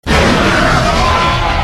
Tnadd2 jumpscare sound
tnadd2-jumpscare-sound.mp3